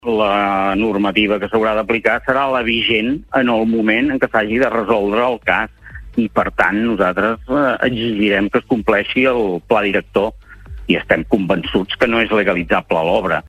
En una entrevista al Supermatí de Ràdio Capital